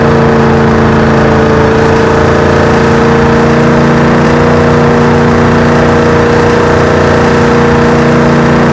charger2012_revdown.wav